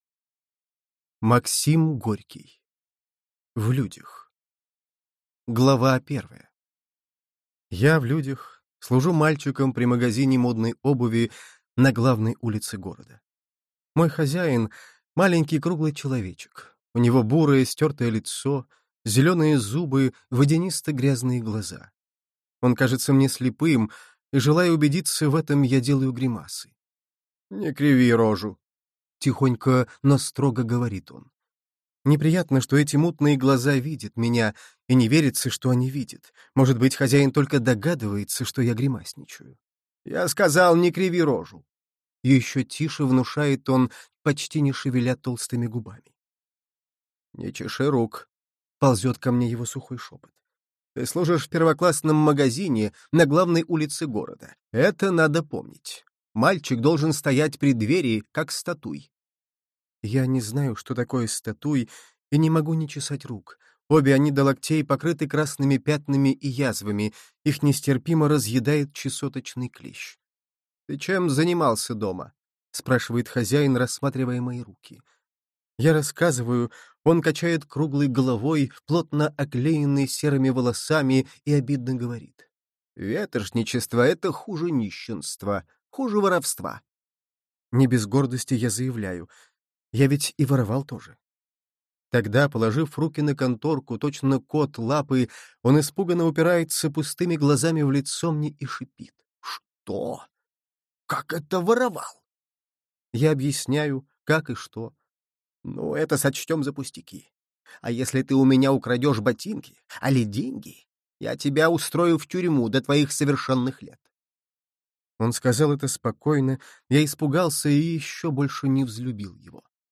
Аудиокнига В людях | Библиотека аудиокниг